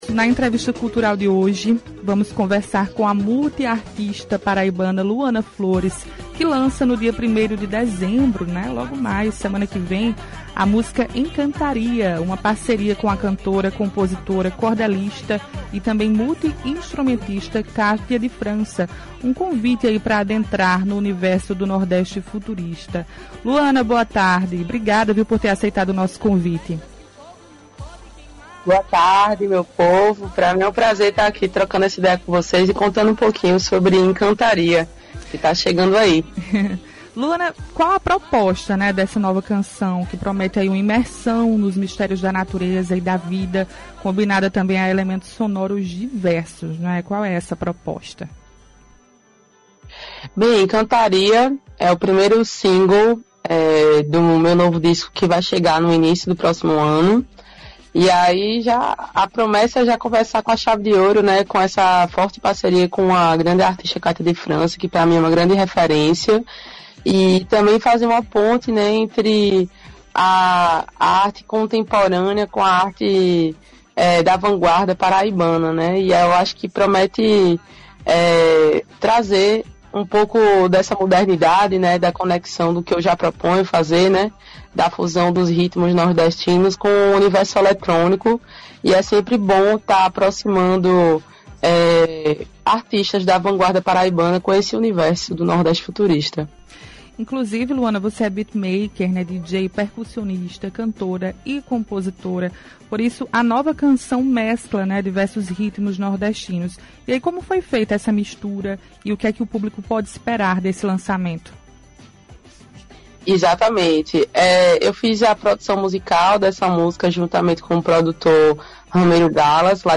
Entrevista Cultural
Clique e ouça a entrevista na íntegra: